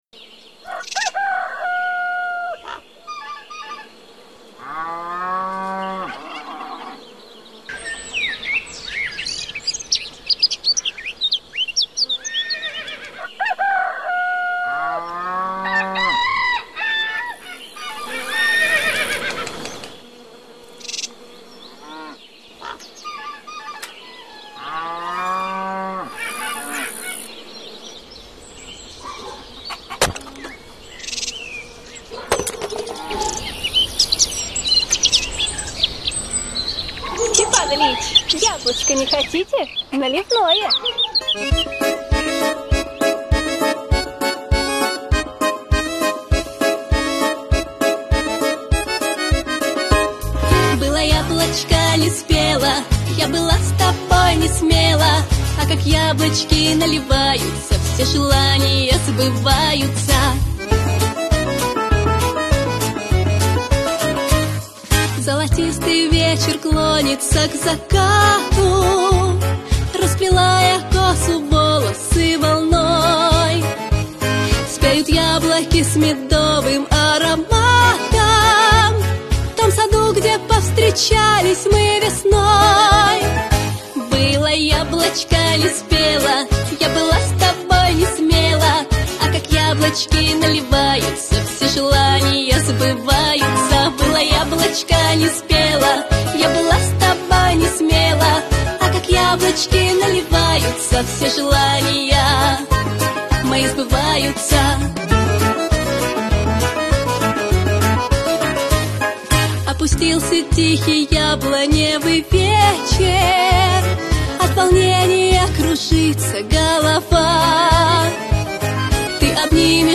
• Качество: Хорошее
• Категория: Детские песни
народный мотив